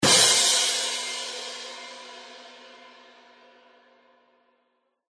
一声钹架子鼓综艺音效免费下载脱口秀选秀语言音效免费下载
SFX音效